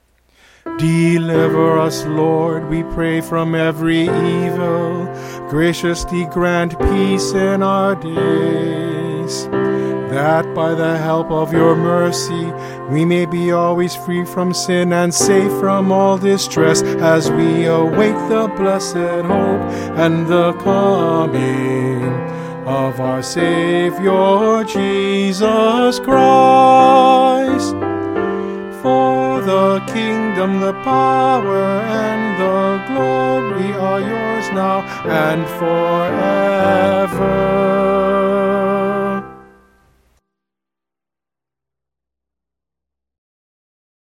Instrumental | Downloadable  GO Download/Print